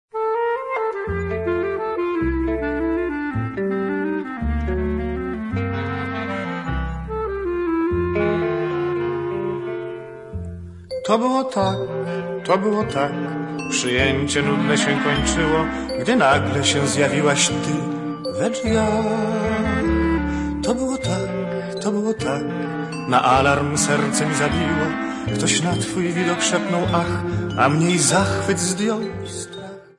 40 Hit Polish Cabaret Songs